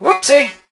barley_throw_02.ogg